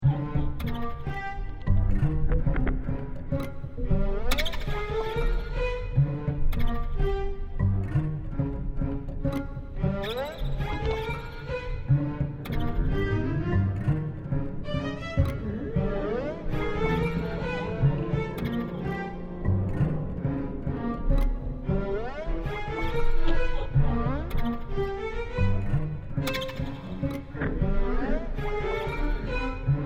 für Streichorchester und Live-Elektronik
Neue Musik
Orchestermusik
Streichorchester
DAT-Aufnahme (CompSimulation)